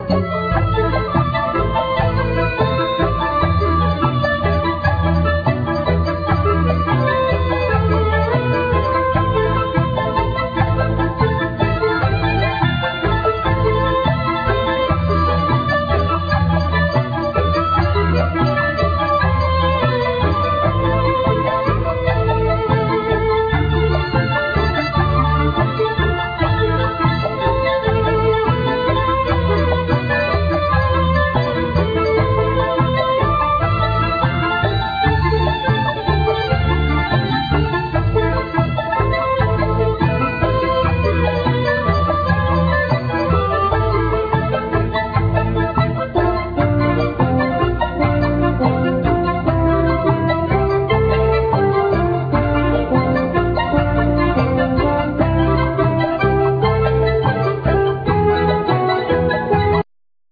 Clarinet
Keyboards,Short Waves
Cello
Percussions
Chapman Stick
Violin
Viola
French Horn
Guitar